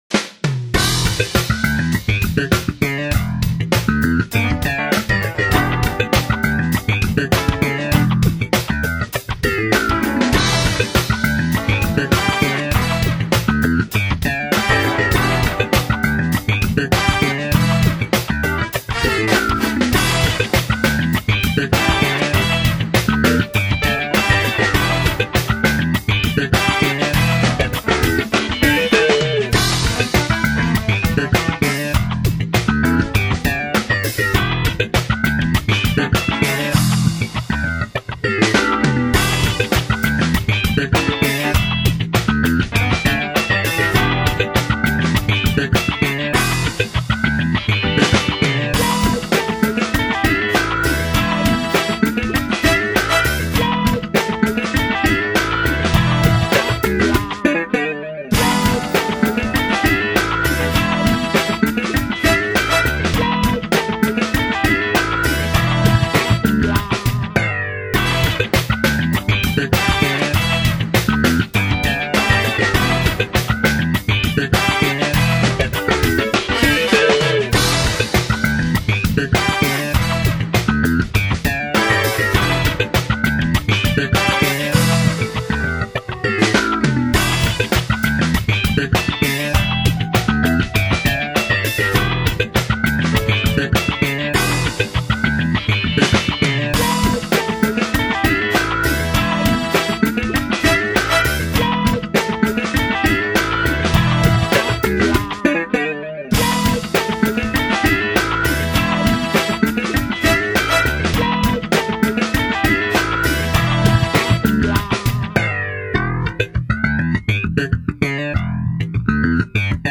play his Smith 4-String!